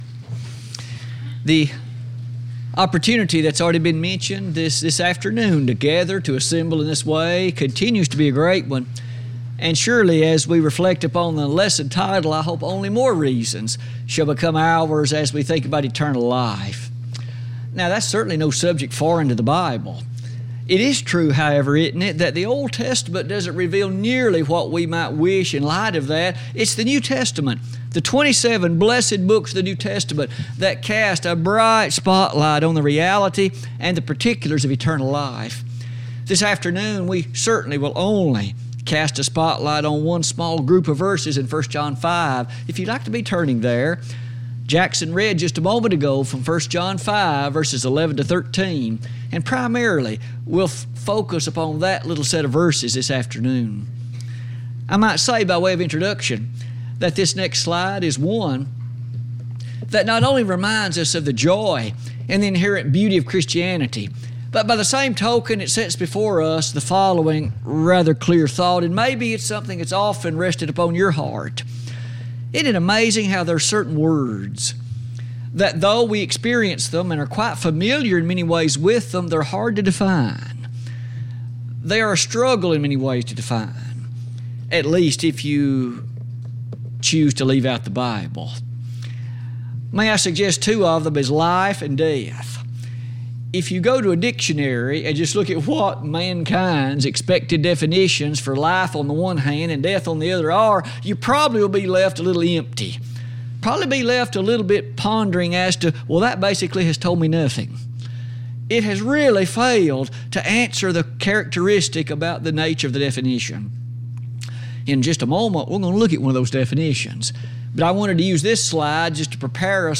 Sermons Recordings